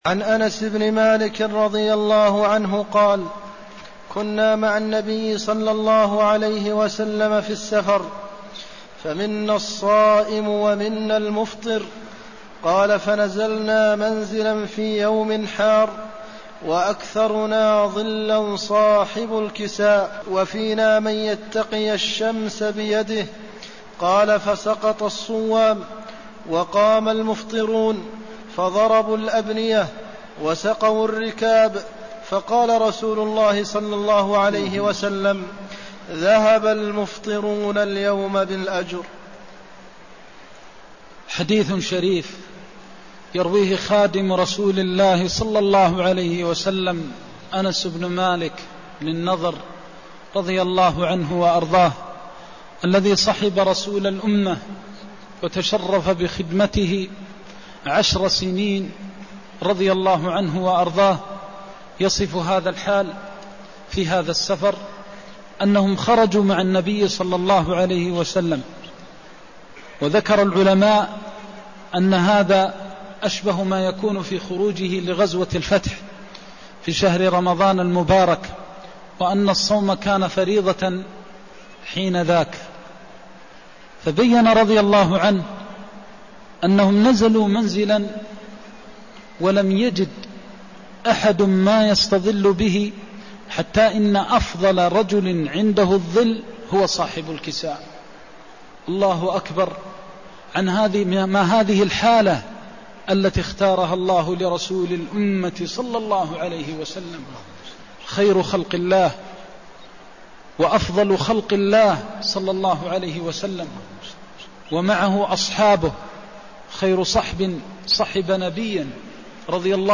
المكان: المسجد النبوي الشيخ: فضيلة الشيخ د. محمد بن محمد المختار فضيلة الشيخ د. محمد بن محمد المختار ذهب المفطرون اليوم بالأجر (181)